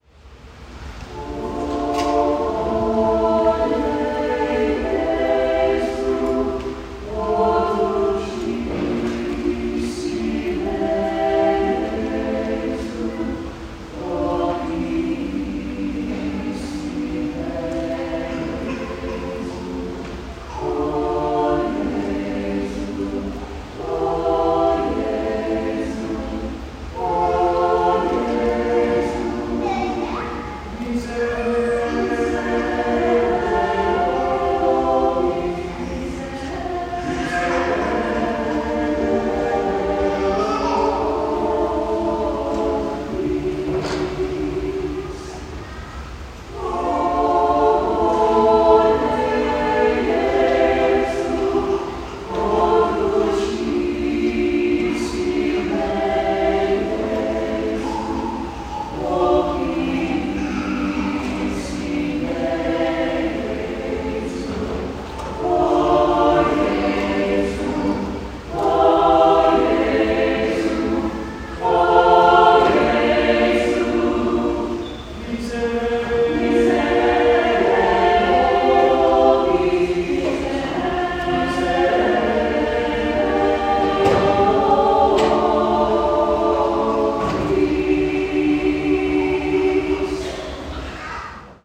* PDF Download • O BONE JESU (For 3 Voices)
“O Bone Jesu” by Michelangelo Grancini is for SAB, but could be a real work-horse for a choir of any size. I used this piece following the Communion Proper on the feast of Christ the King.
To listen to my fully volunteer Schola Cantorum sing this piece, please click here.